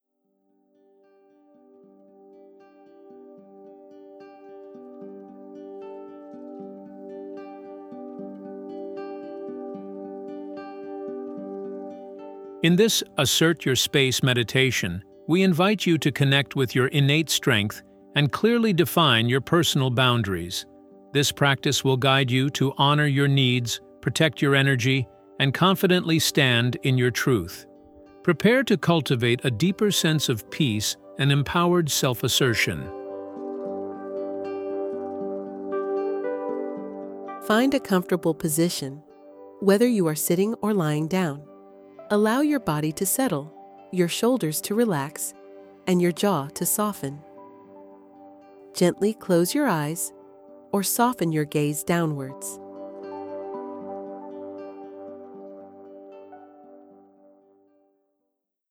‘Assert Your Space’ Guided Meditation
Preview_Assert-Yourself-Meditation.mp3